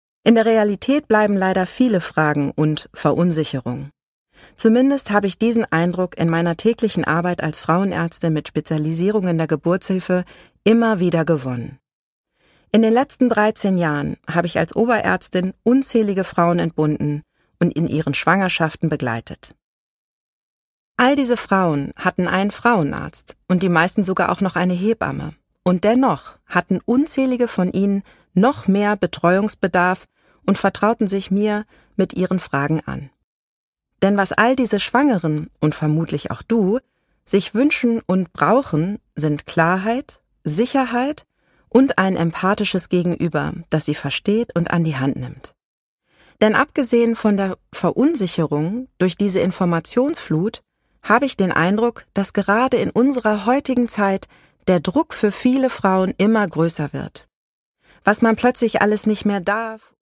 Produkttyp: Hörbuch-Download
MP3 Hörbuch-Download